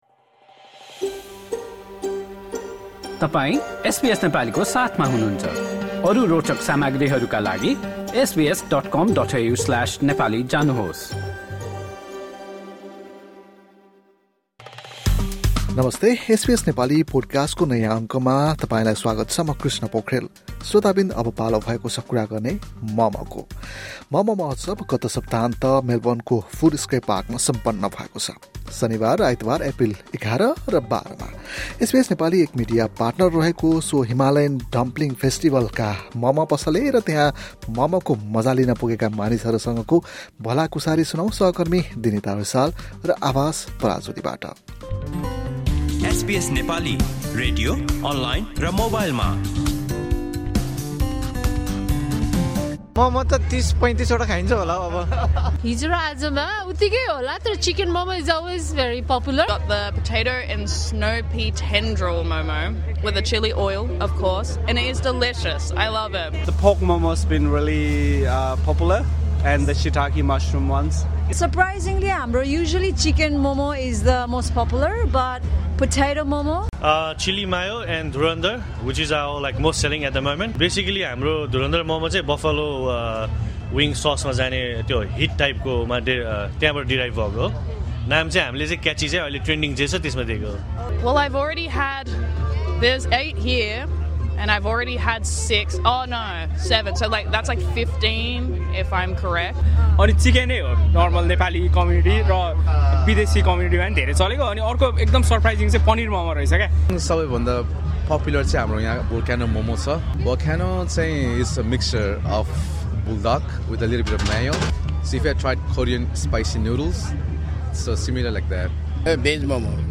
From the sizzle of steaming momo to the buzz of a multicultural crowd, Melbourne's Footscray Park came alive last weekend with the Himalayan Dumpling Festival.
Listen to our conversation with vendors and visitors as they share their stories, flavours and connections beyond the plate.